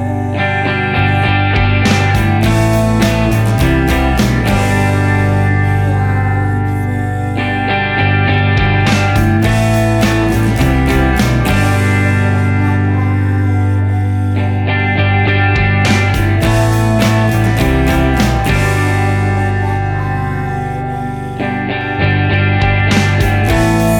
no Backing Vocals Indie / Alternative 3:29 Buy £1.50